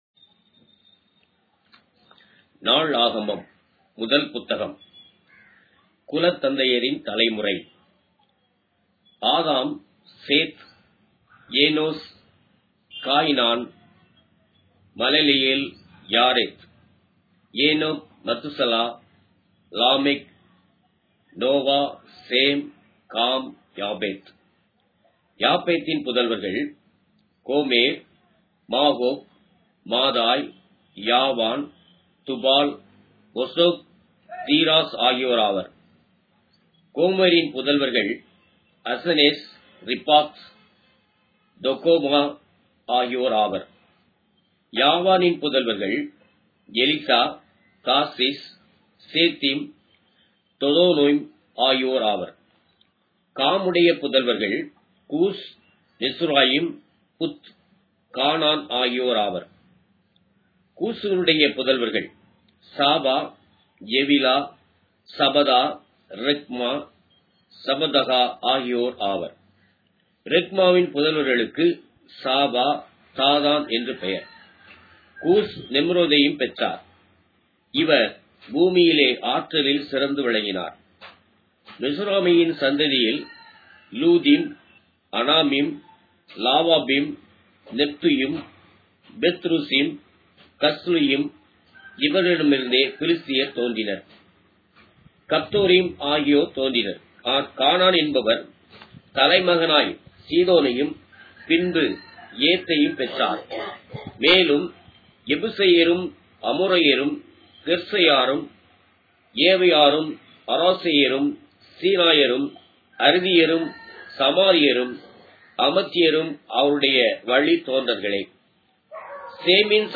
Tamil Audio Bible - 1-Chronicles 11 in Rcta bible version